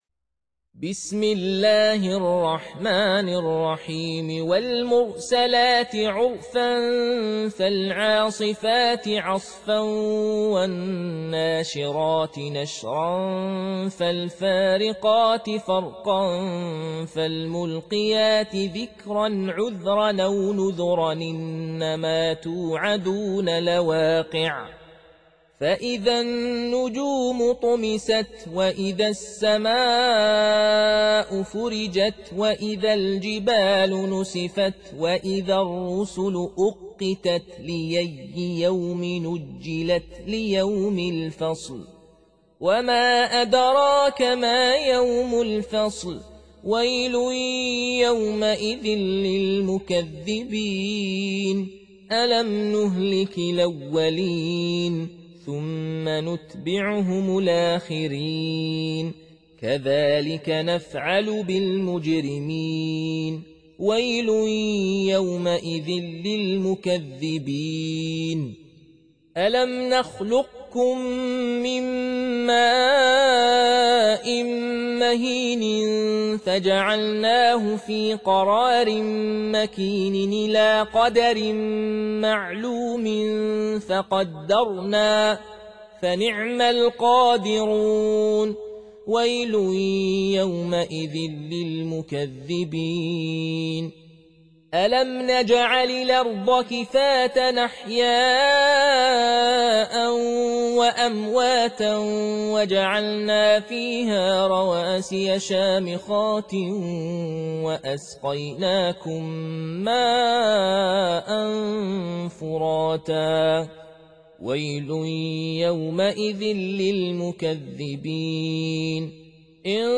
Surah Sequence تتابع السورة Download Surah حمّل السورة Reciting Murattalah Audio for 77. Surah Al-Mursal�t سورة المرسلات N.B *Surah Includes Al-Basmalah Reciters Sequents تتابع التلاوات Reciters Repeats تكرار التلاوات